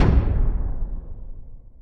q3rally/baseq3r/sound/movers/plats/pt1_end.ogg at e1122a8a45ffea5bbabbd4a7af3711c6a22bba55